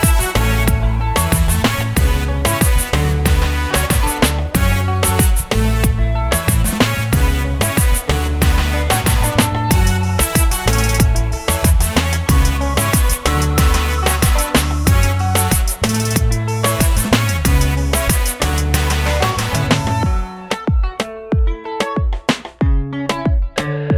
for solo male Pop (2010s) 3:09 Buy £1.50